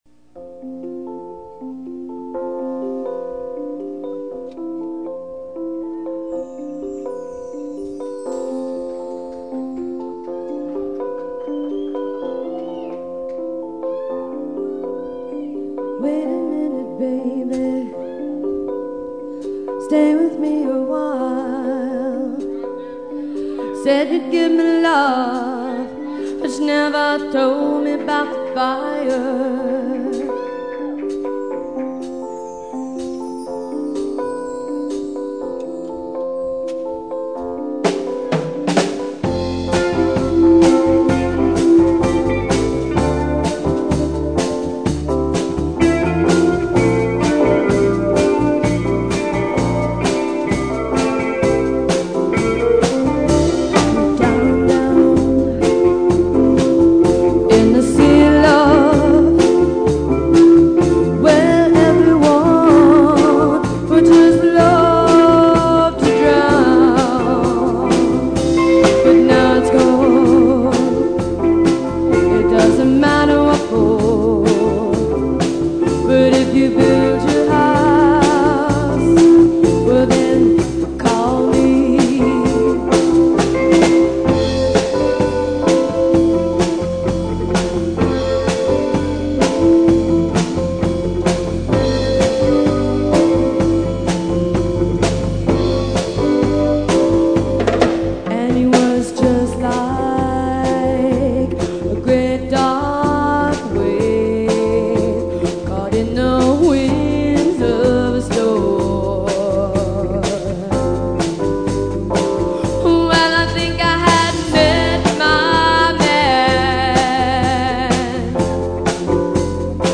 ROCK 'N ROLL
Live At L. C. Saloon 1/16/83 + Bonus Tracks